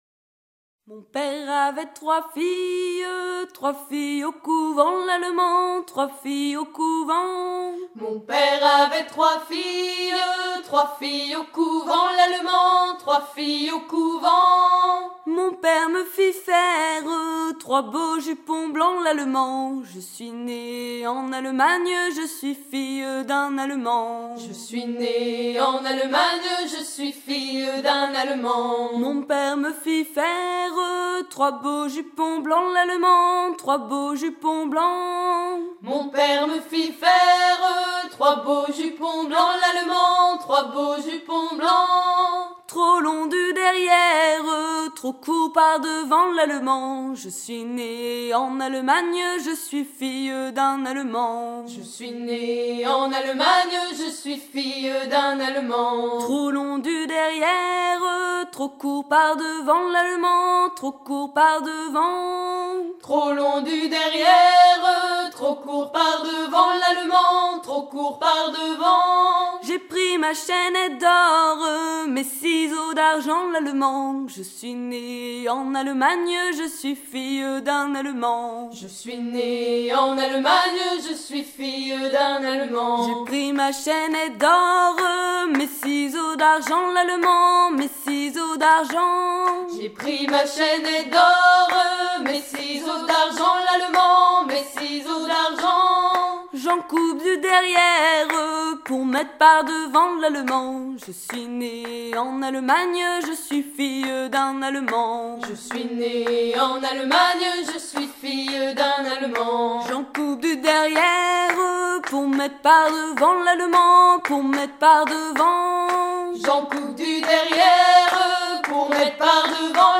à virer au cabestan
Pièce musicale éditée